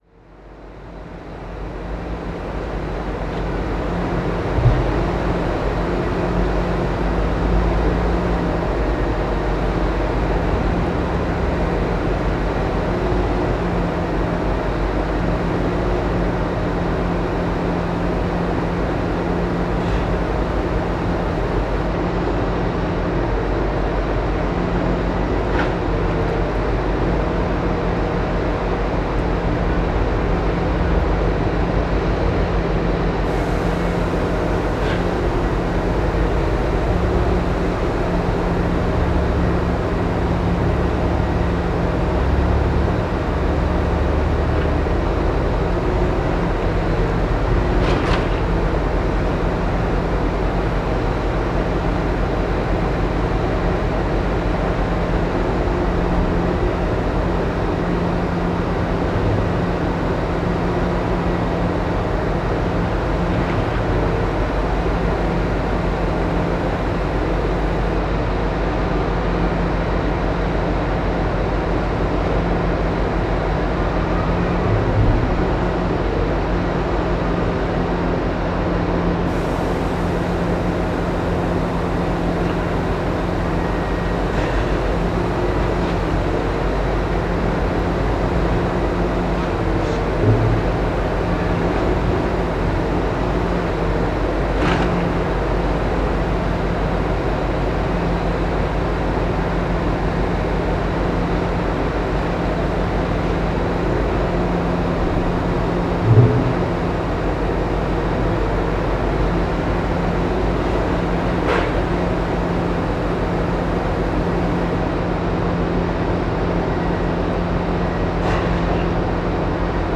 Paisagem sonora de Serração junto à Junta de Freguesia de Lordosa, Lordosa a 19 Fevereiro 2016.
Num dia de Inverno junto à Extensão de Saúde de Lordosa / Calde.